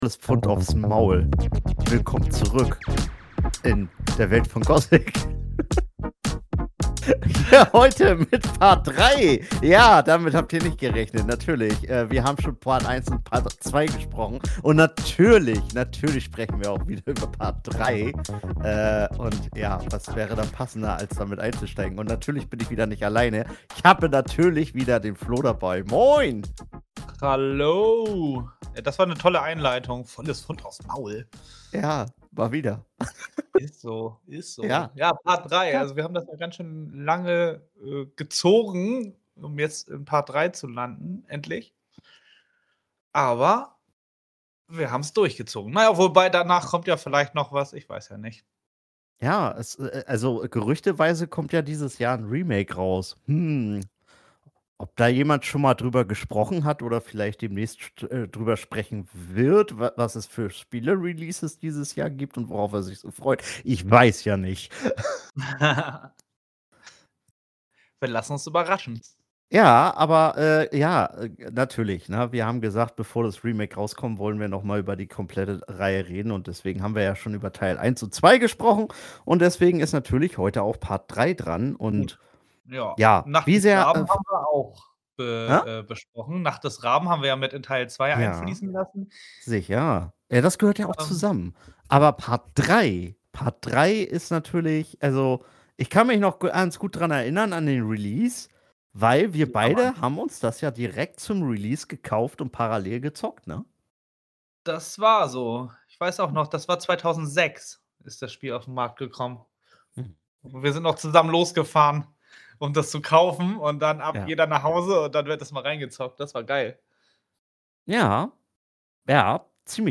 Gothic 3 im Talk!